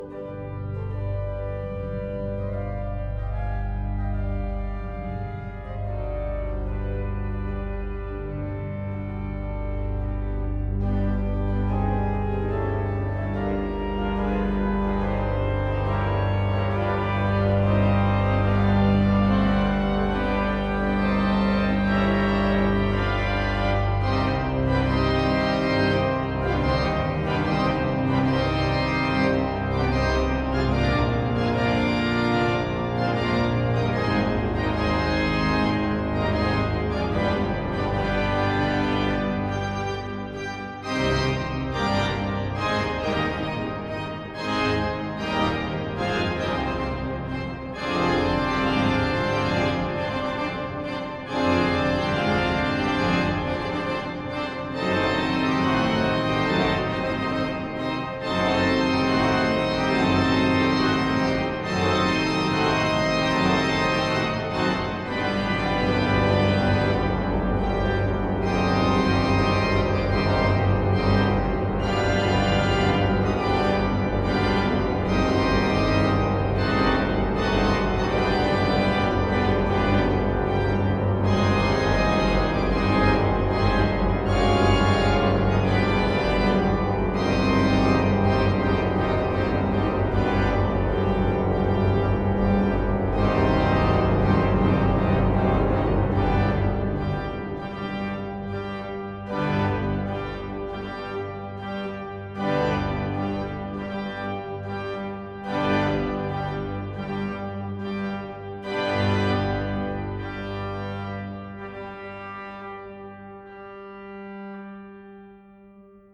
Hören Sie sich einige Stücke an, gesungen von der Kantorei und dem JuLifa-Chor (Junge Lieder für alle) der Pfarrei Herz Jesu oder gespielt an der Schuke-Orgel der Stadtpfarrkirche!